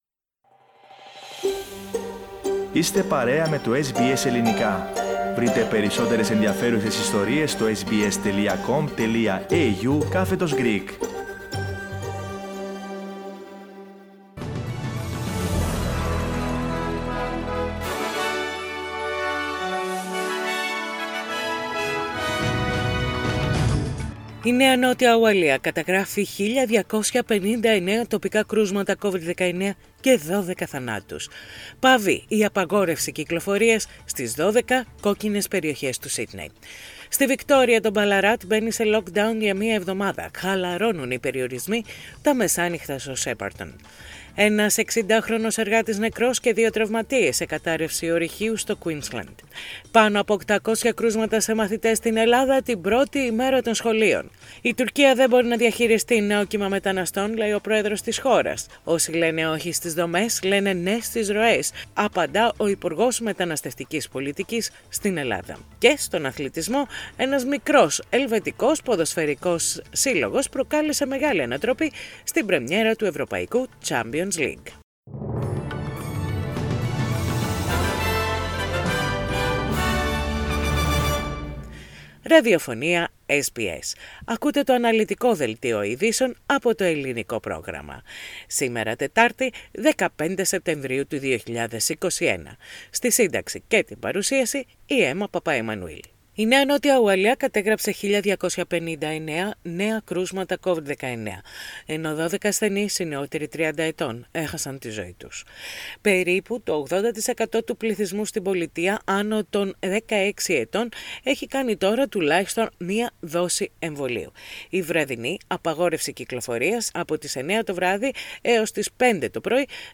News in Greek - Wednesday 15.9.21